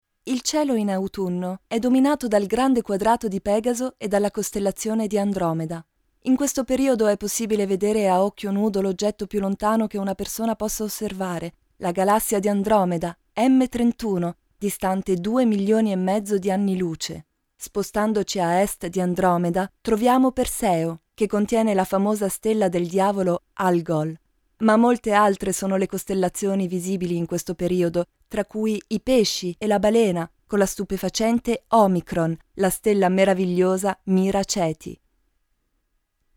意大利语翻译团队成员主要由中国籍和意大利籍的中意母语译员组成，可以提供证件类翻译（例如，驾照翻译、出生证翻译、房产证翻译，学位证翻译，毕业证翻译、成绩单翻译、无犯罪记录翻译、营业执照翻译、结婚证翻译、离婚证翻译、户口本翻译、奖状翻译等）、公证书翻译、病历翻译、意语视频翻译（听译）、意语语音文件翻译（听译）、技术文件翻译、工程文件翻译、合同翻译、审计报告翻译等；意大利语配音团队由意大利籍的意大利语母语配音员组成，可以提供意大利语专题配音、意大利语广告配音、意大利语教材配音、意大利语电子读物配音、意大利语产品资料配音、意大利语宣传片配音、意大利语彩铃配音等。
意大利语样音试听下载